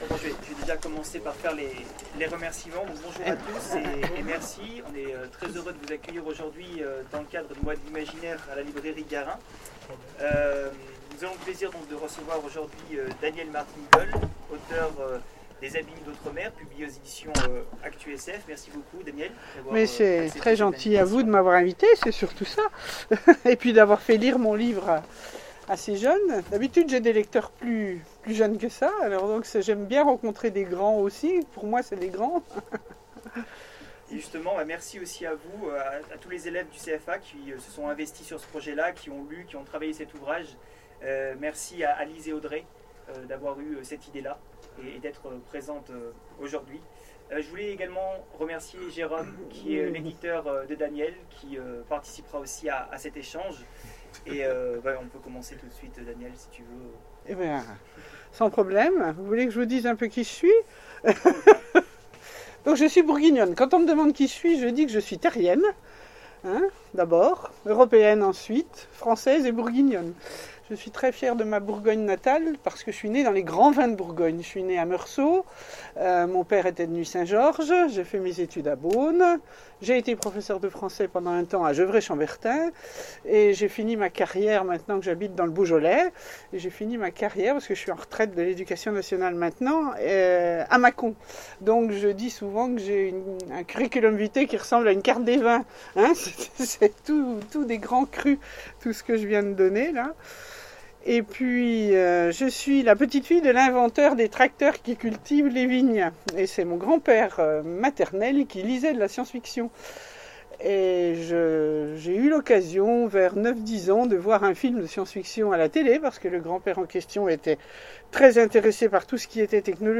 Mots-clés Jeunesse Conférence Partager cet article